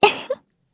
Xerah-Vox_Happy1_kr.wav